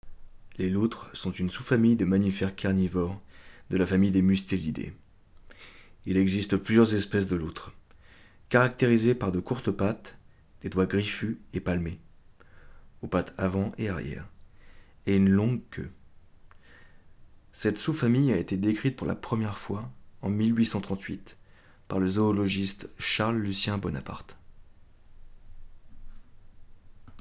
docu